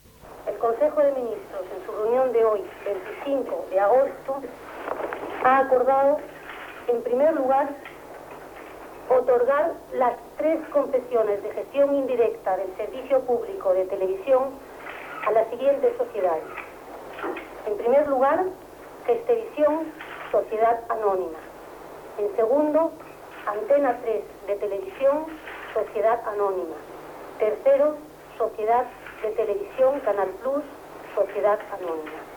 La portaveu Rosa Conde anuncia l'acord del Consell de Ministres espanyol per atorgar les tres llicències de televisió privada a Antena 3 de Televisión, Gestevisión Telecinco i Sogecable
Informatiu